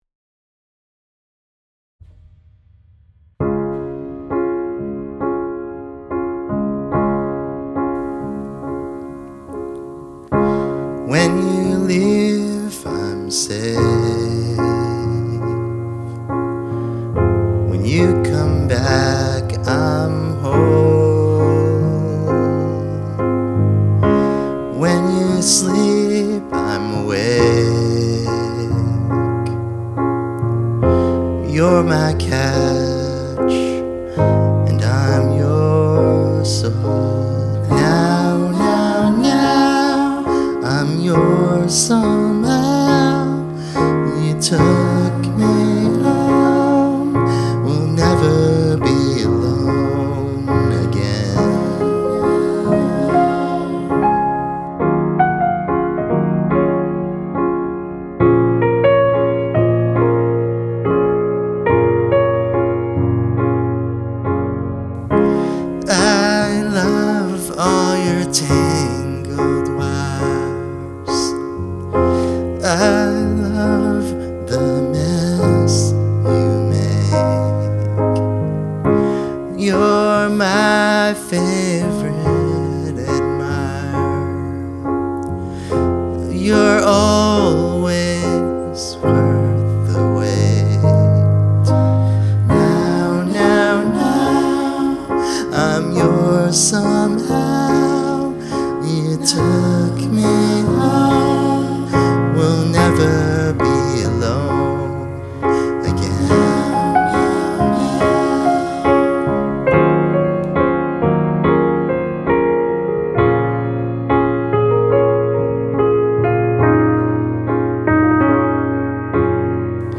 Love the meows.
"meow meow meow" how perfect!